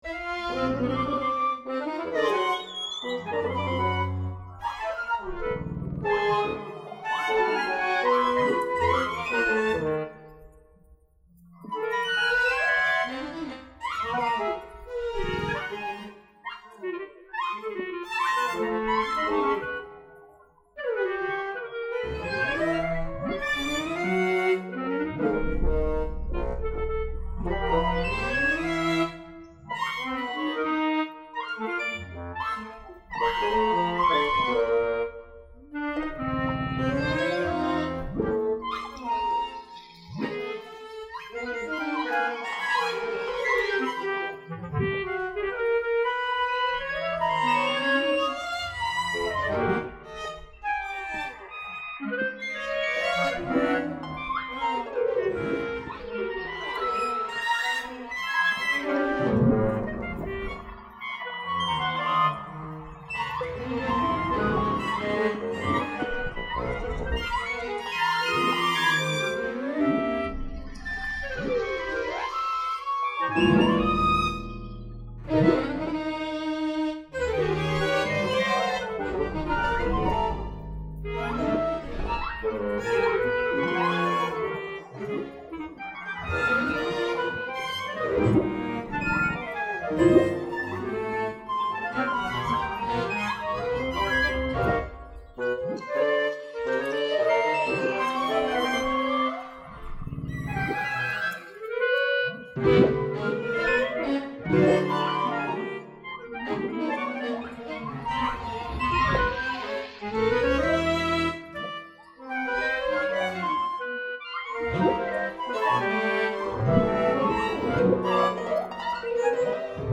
Pour piccolo, flûte, flûte basse, Clarinette, Clarinette basse, basson, ultrabasson,
3 violons, alto, violoncelle, contrebasse,
2 harpes
Conçues à l’origine comme des tests techniques, ces transcriptions impossibles étudient le passage entre un enregistrement audio & une orchestration midi.
D’après Baleines à bosse enregistrées à l'hydrophone DPA au large de saint Paul de la réunion
renforcer dans les basses, travailler des glissandi  ?